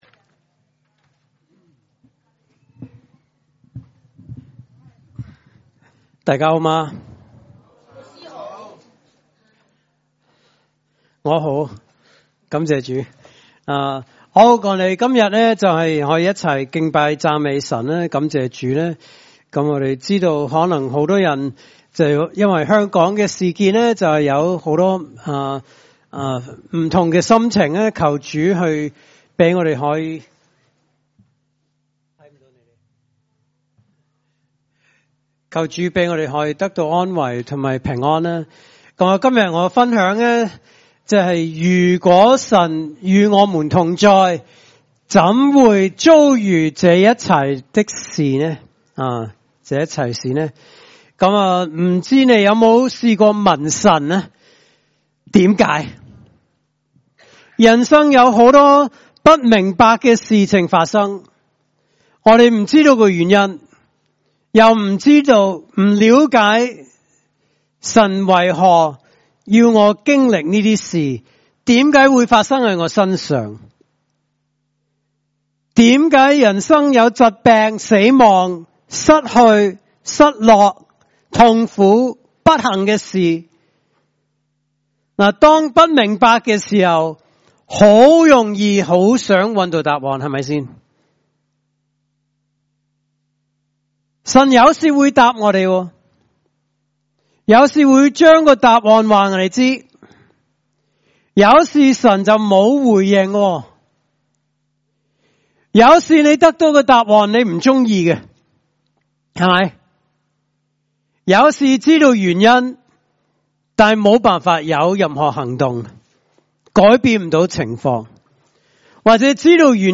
來自講道系列 "解經式講道"